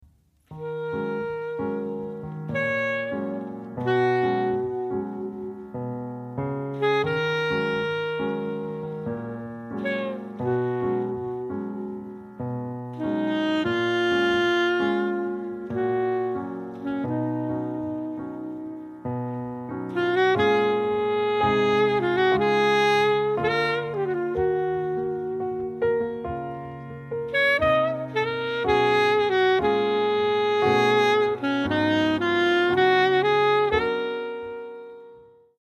Recorded at Red Gables Studio 8th, 9th May 2008